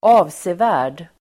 Uttal: [²'a:vse:vä:r_d]